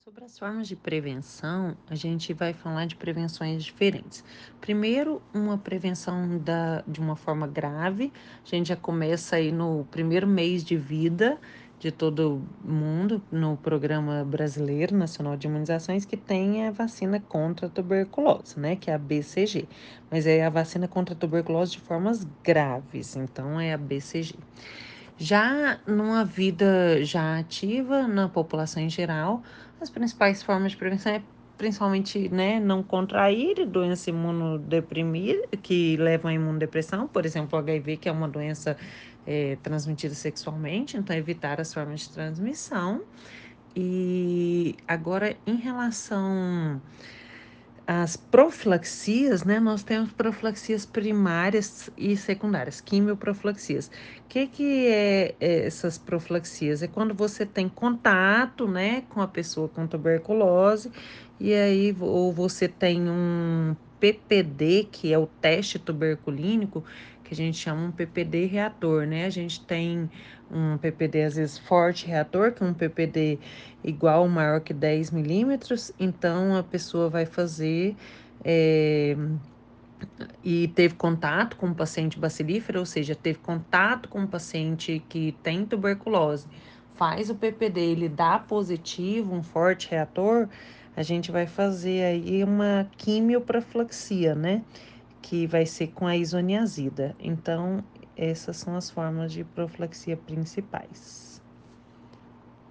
Especialista explica como se prevenir e tratar da doença que é considerada um problema de saúde pública